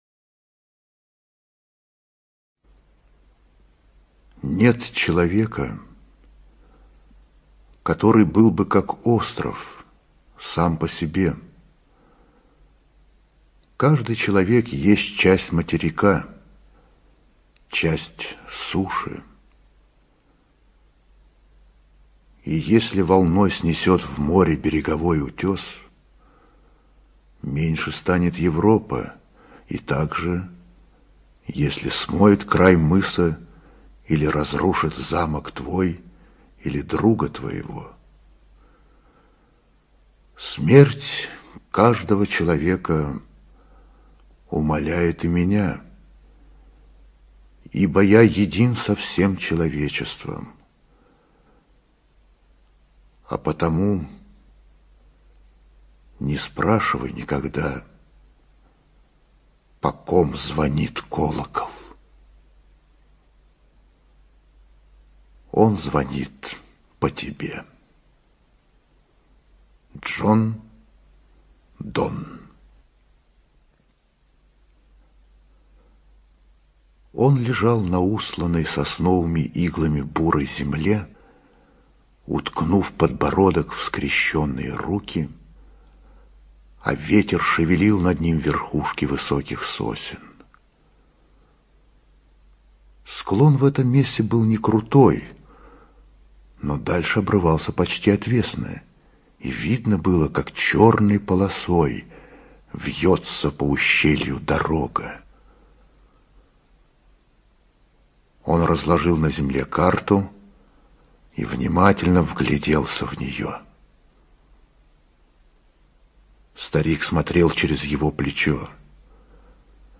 ЧитаетВиторган Э.